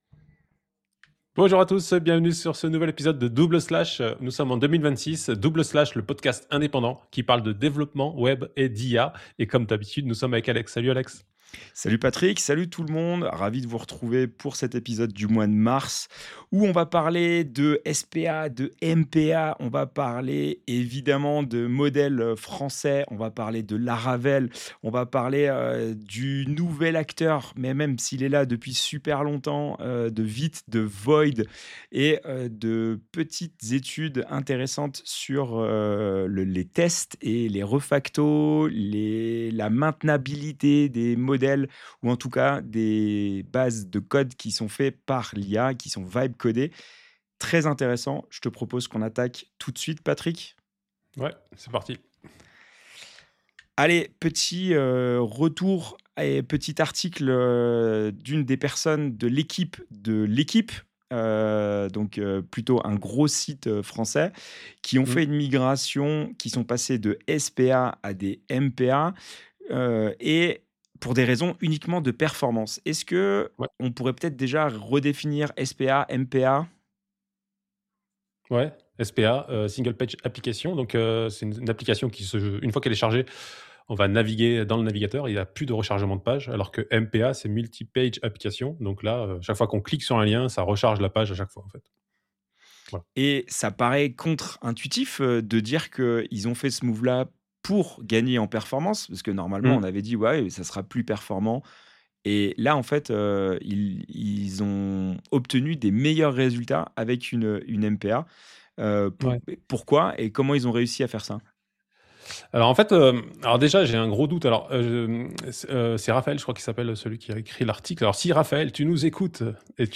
Double Slash, un podcast avec 2 animateurs qui se retrouvent pour discuter des outils et des techniques pour le développement moderne de site web et d’application web. Retrouvez-nous régulièrement pour parler de sujets variés tels que la JAMStack, l’accessibilité, l’écoconception, React JS, Vue JS et des retours d’expériences sur des implémentations.
Nous serons accompagnés de temps en temps par des invités experts dans leur domaine pour approfondir un sujet et avoir des retours d’expériences.